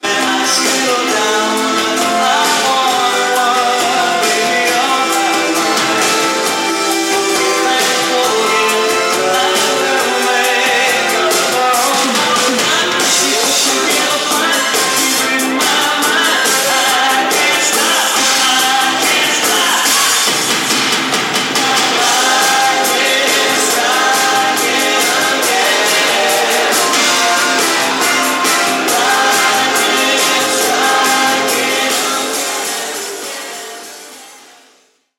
1985 Summer Rehearsal - Act 1!